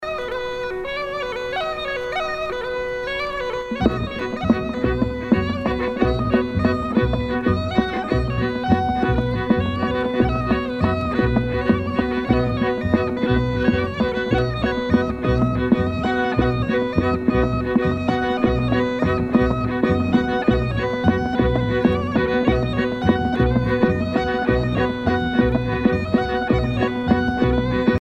Usage d'après l'analyste gestuel : danse
Catégorie Pièce musicale éditée